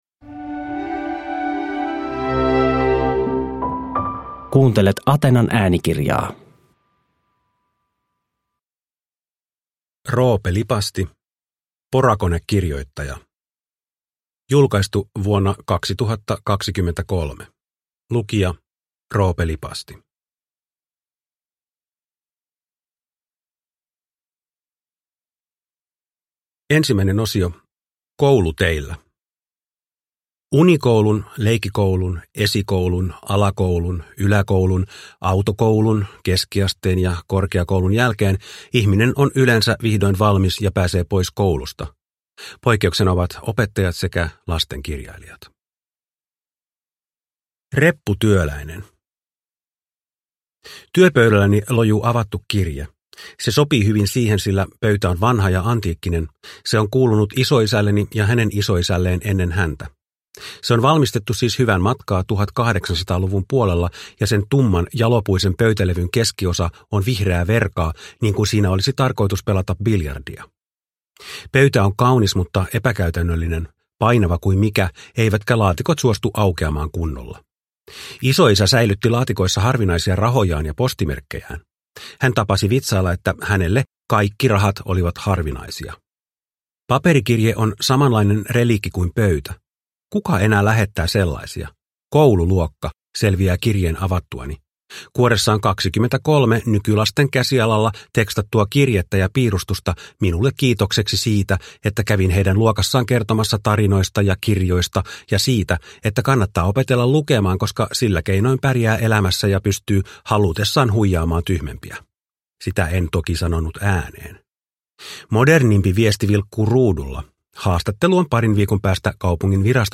Porakonekirjoittaja – Ljudbok
Uppläsare: Roope Lipasti